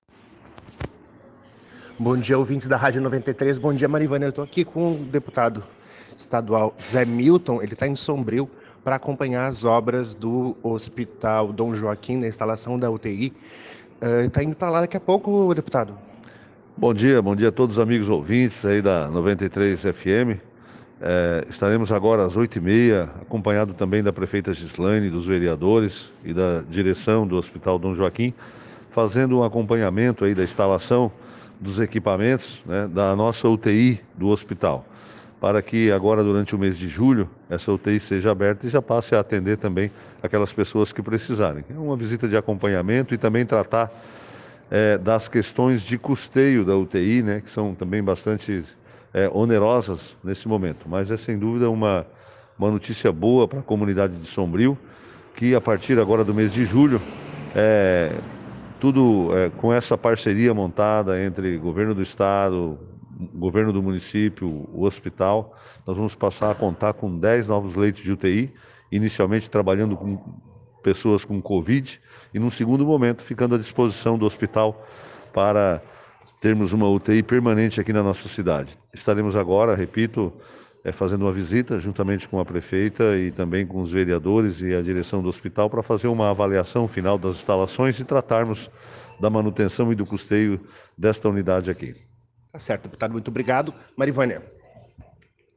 na Rádio 93 FM, o deputado estadual José Milton Scheffer, em conversa com a reportagem